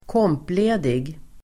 Ladda ner uttalet
kompledig adjektiv (särskilt inom yrken med jourtjänst), on compensatory leave [used especially of workers who must be on call] Uttal: [²k'åm:ple:dig] Böjningar: kompledigt, komplediga Definition: ledig som kompensation för övertidsarbete